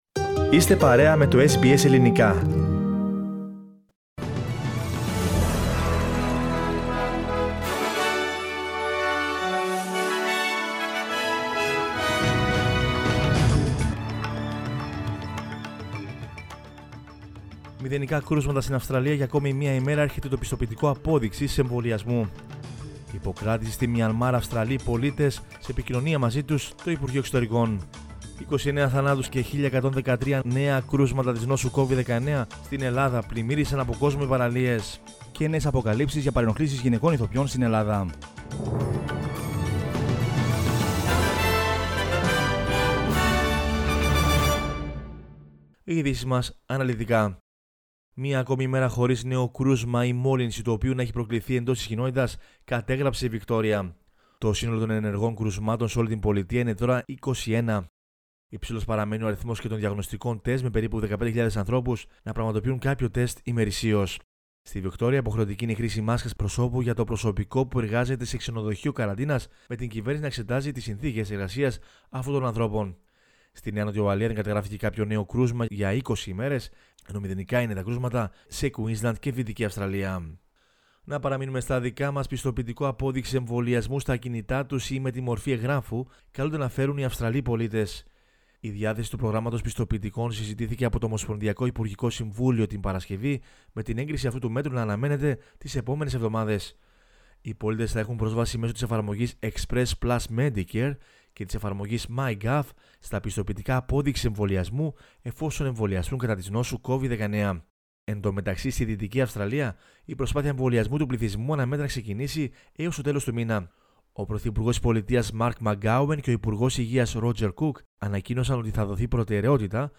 News in Greek from Australia, Greece, Cyprus and the world is the news bulletin of Sunday 7 February 2021.